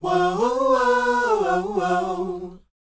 WHOA F#C.wav